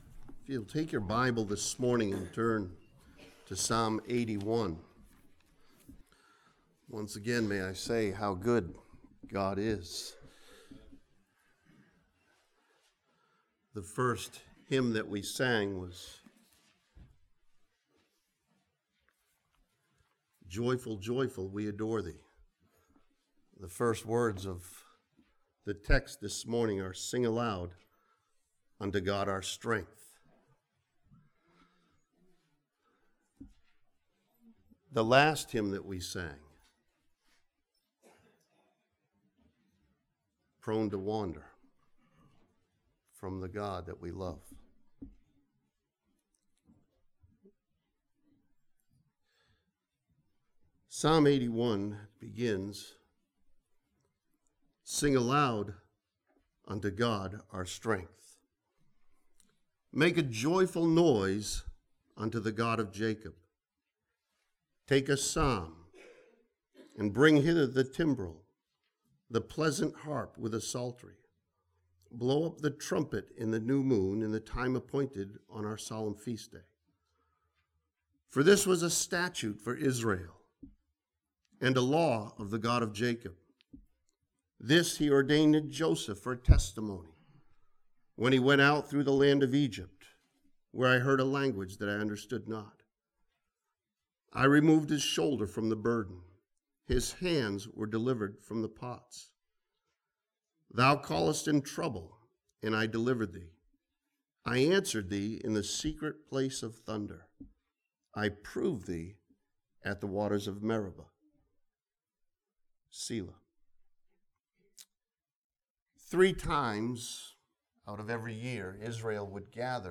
This sermon from Psalm 81 challenges believers to hear the message from God found in this Psalm.